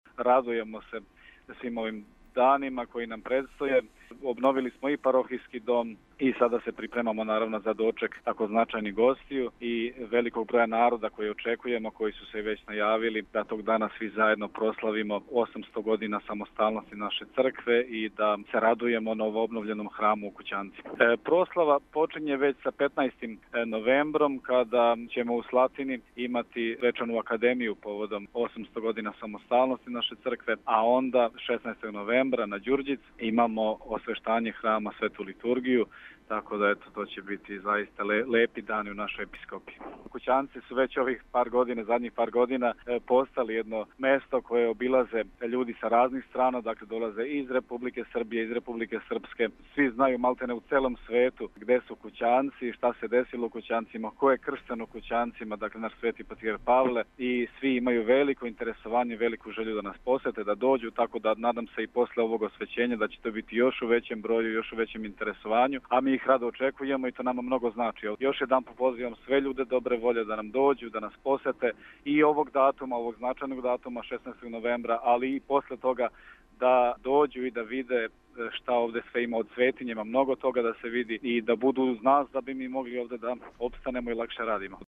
Део разговора